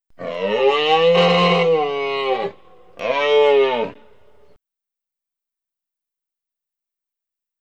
Dinosaur King Altirhinus Bellow
Category: Sound FX   Right: Personal